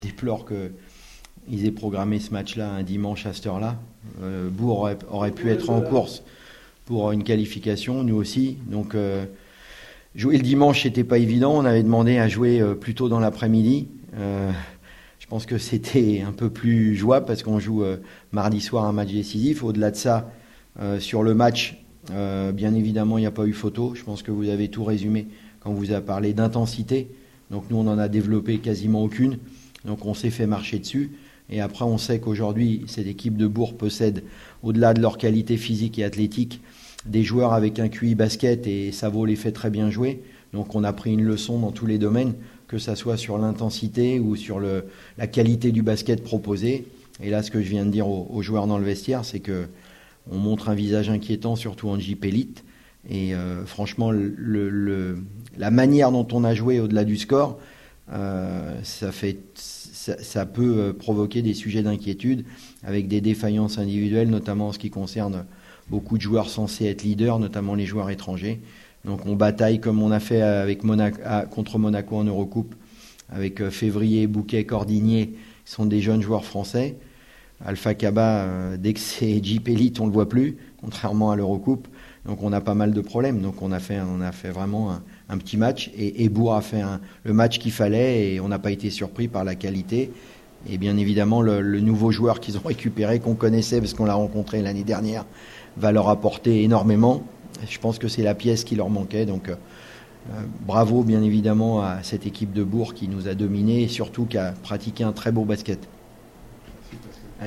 LES Réactions